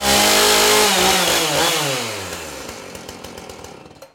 chainsaw.ogg